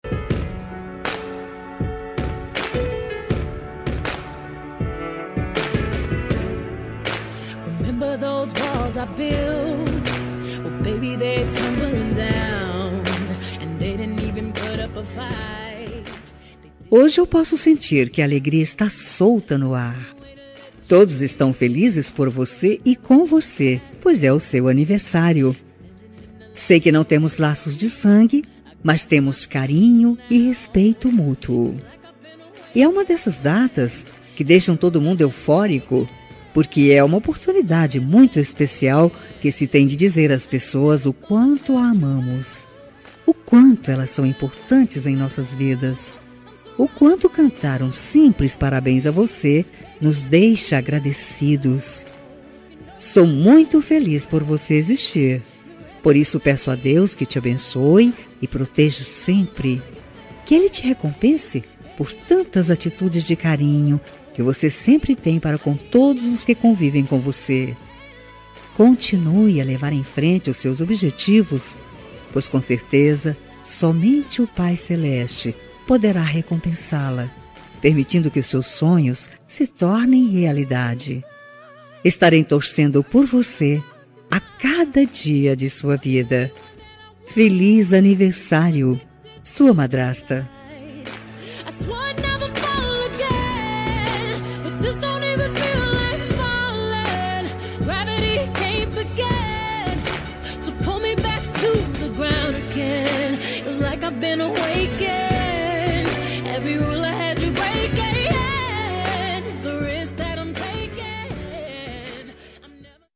Aniversário de Enteada – Voz Feminina – Cód: 4034